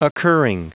Prononciation du mot occurring en anglais (fichier audio)
Prononciation du mot : occurring